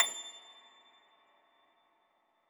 53k-pno25-A5.aif